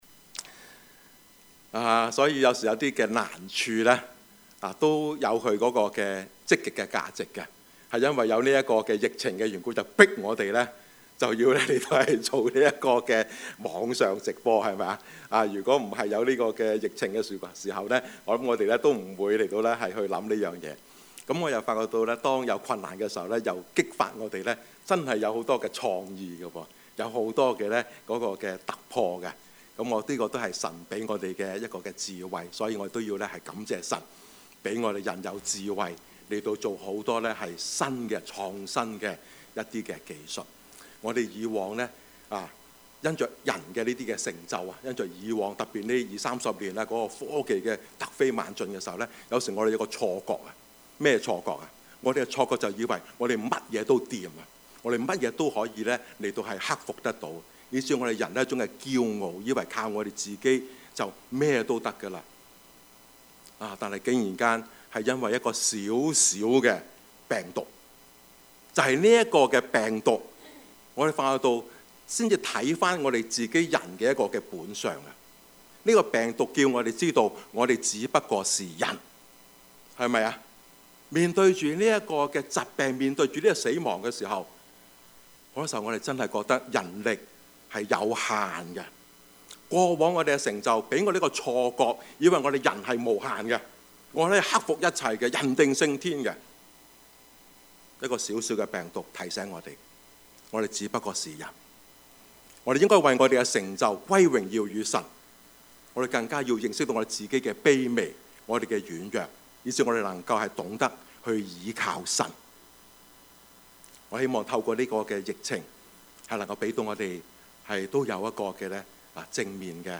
Series: 2020 主日崇拜